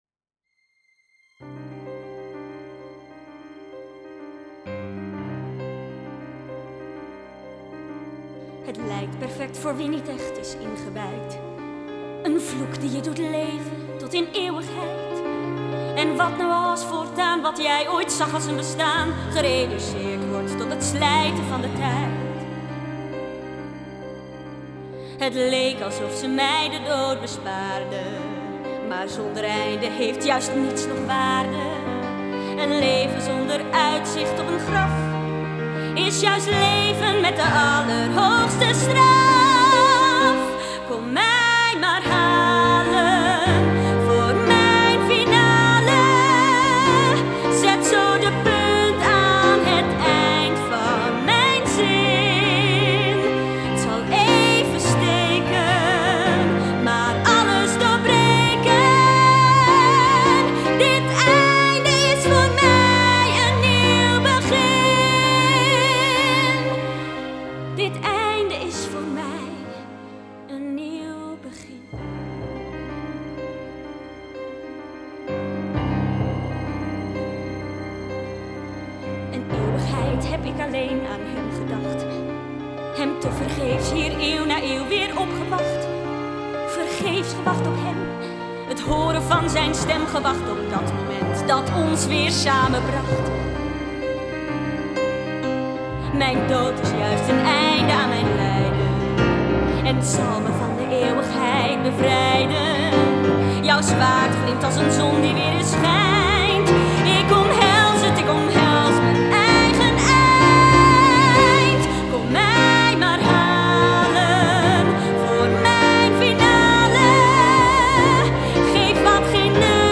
1.Een einde is een nieuw begin - Demo